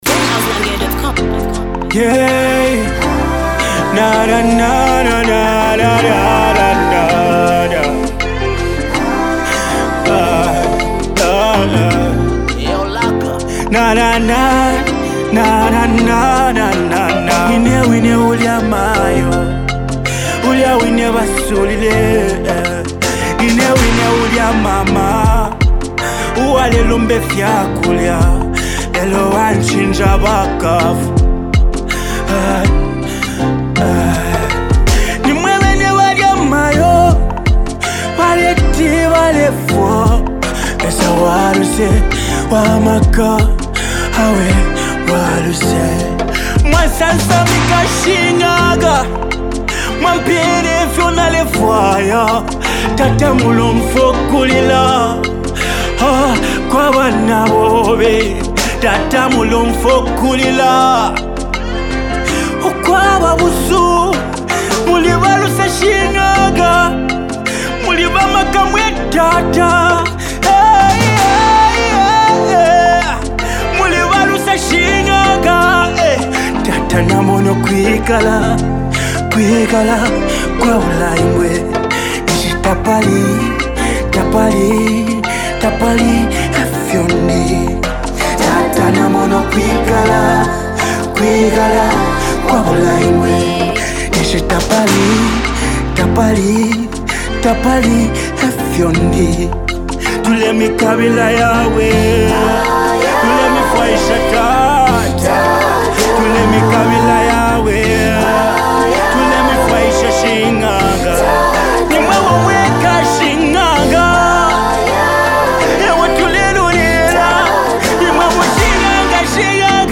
powerful spiritual energy
With touching lyrics and uplifting melodies